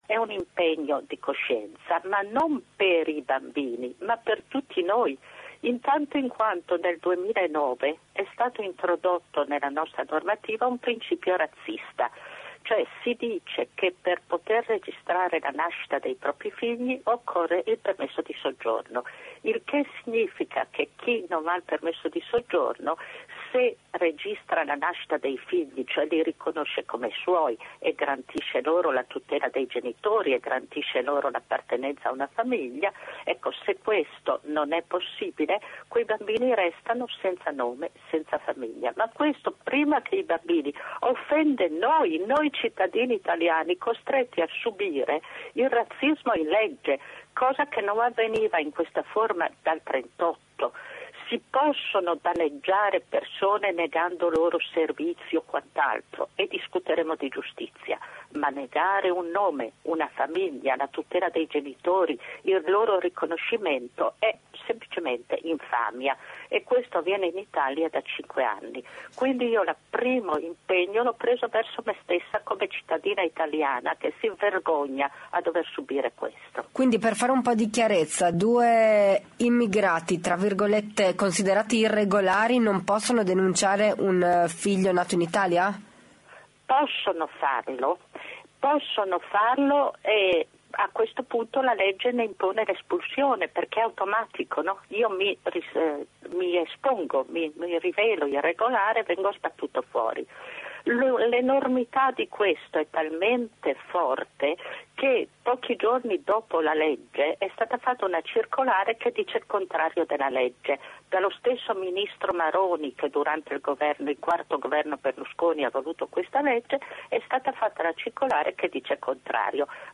Udine che mi ha intervistato e inserito l’intervista anche su facebook.